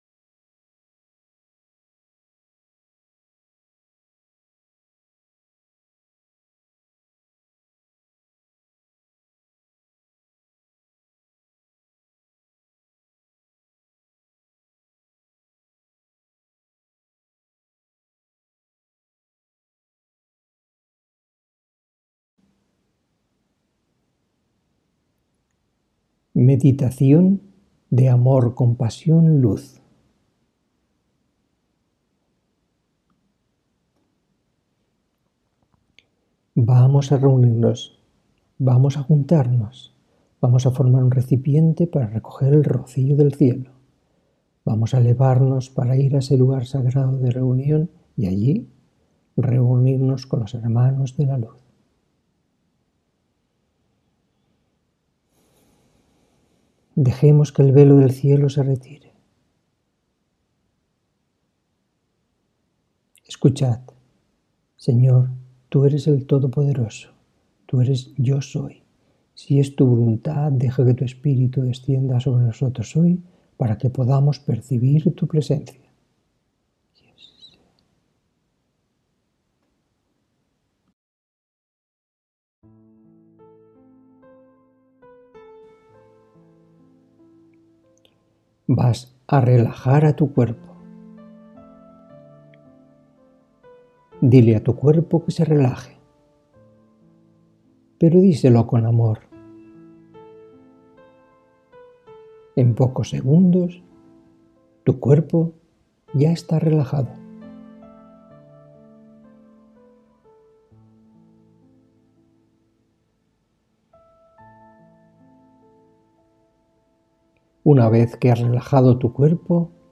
Meditación